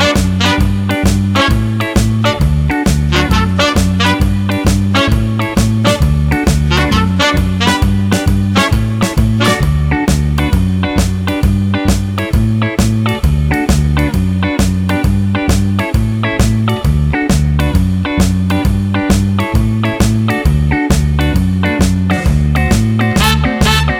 No Harmonica Oldies (Female) 2:07 Buy £1.50